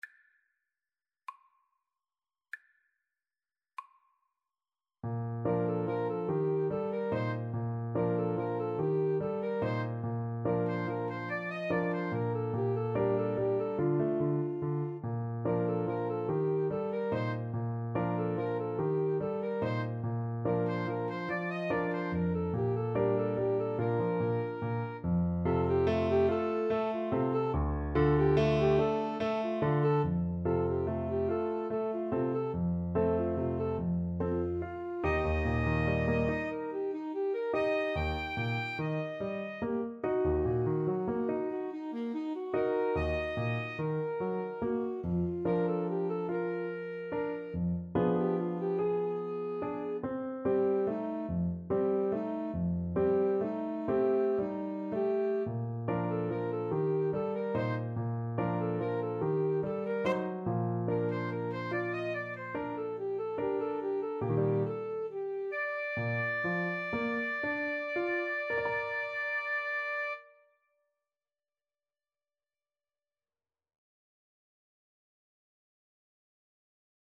Play (or use space bar on your keyboard) Pause Music Playalong - Player 1 Accompaniment reset tempo print settings full screen
Bb major (Sounding Pitch) C major (Clarinet in Bb) (View more Bb major Music for Clarinet-Saxophone Duet )
Gently rocking = 144
6/8 (View more 6/8 Music)